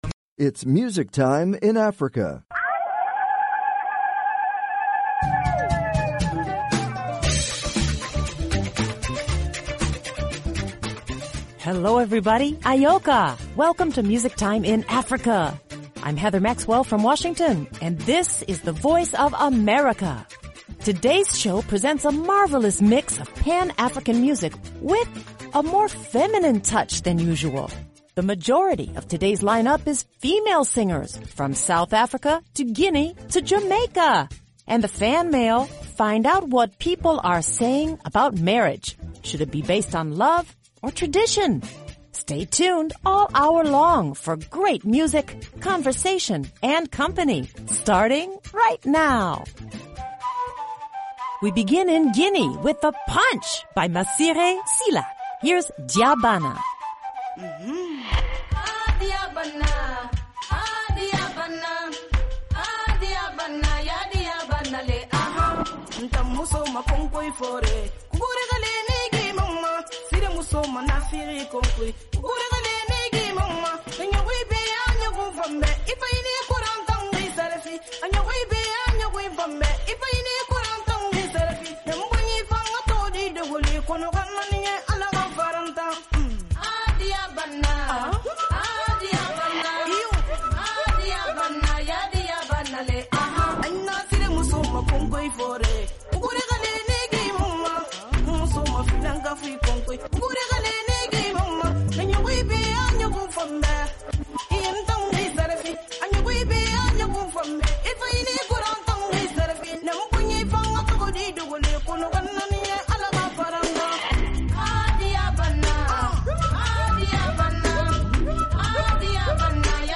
Since 1965, this award-winning program has featured pan African music that spans all genres and generations.
exclusive interviews